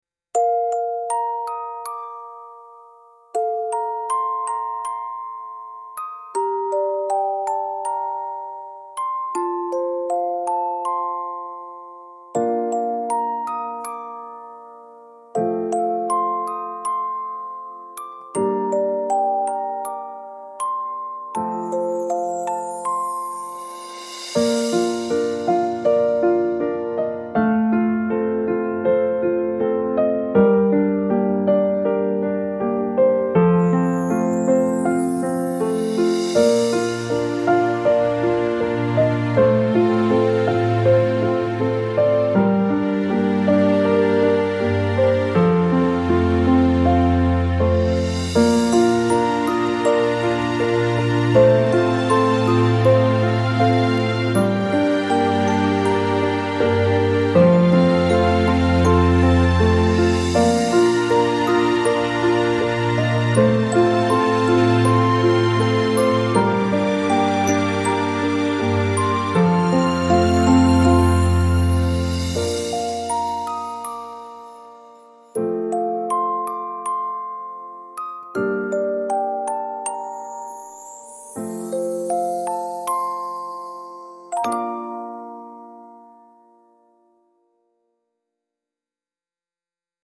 gentle lullaby-like melody with music box, soft piano and strings